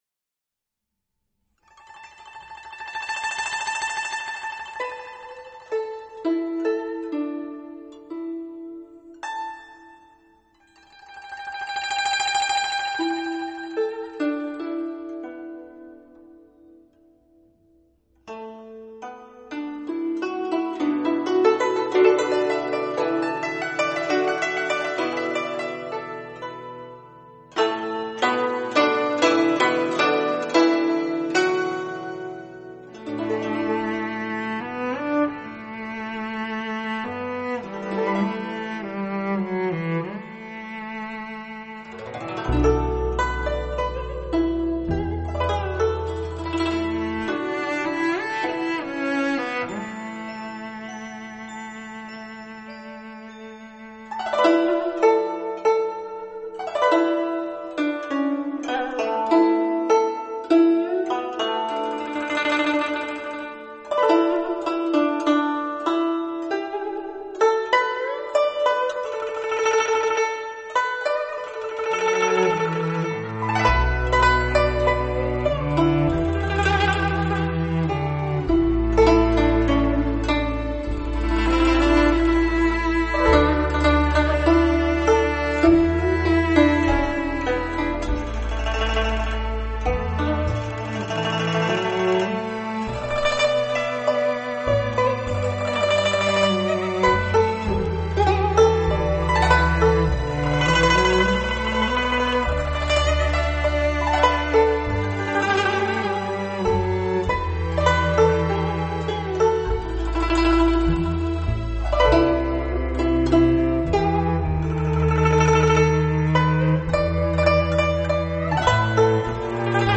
筝声飞扬 弦韵悠悠
音色淳厚优美 典雅华丽而委婉 ，配上如诗如风的吟唱，